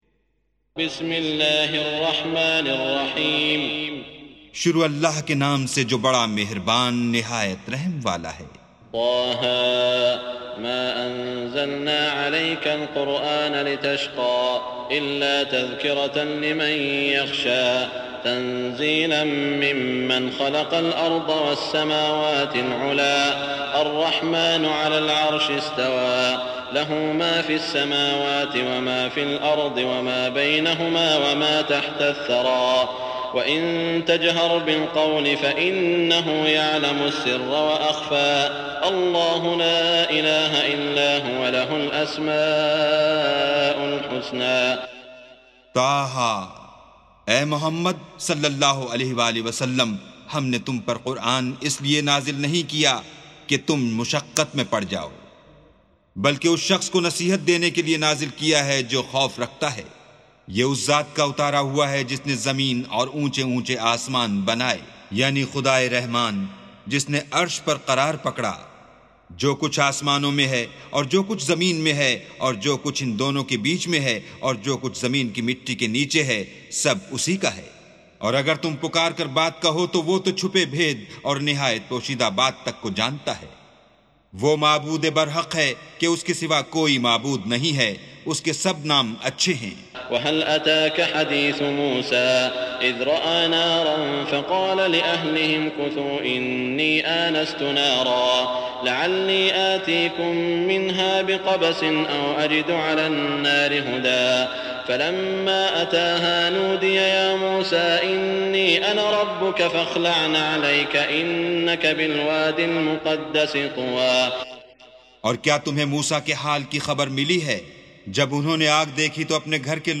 سُورَةُ طه بصوت الشيخ السديس والشريم مترجم إلى الاردو